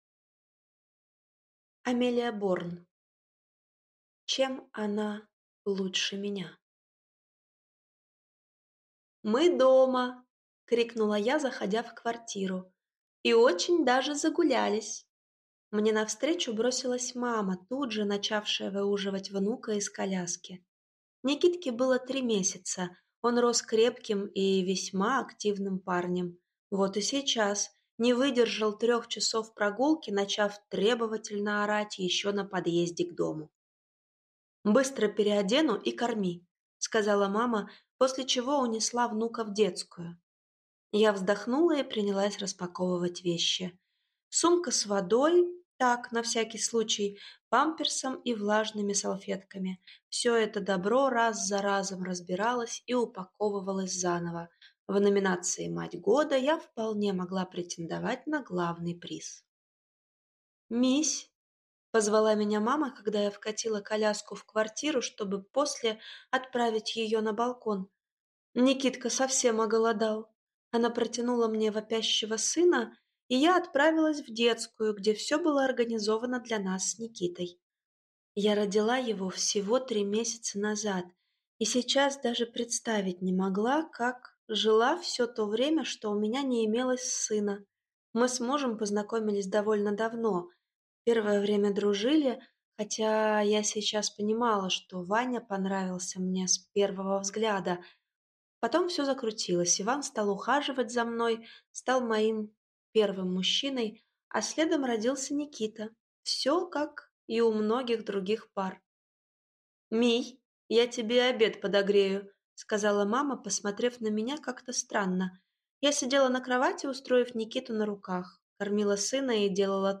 Бездомыш. Предземье (слушать аудиокнигу бесплатно) - автор Андрей Рымин